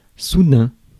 Ääntäminen
IPA: [su.dɛ̃]